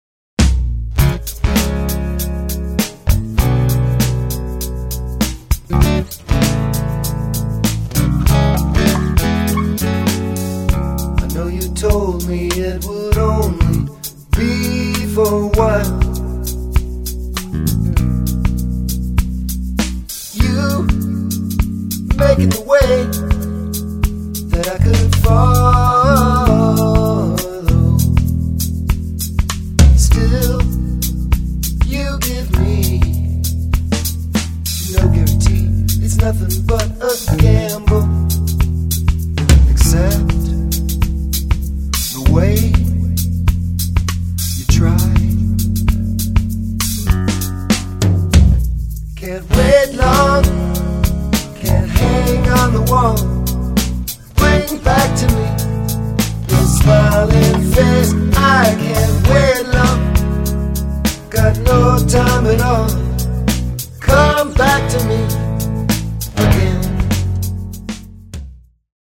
percussion from around the globe
poly-metric funk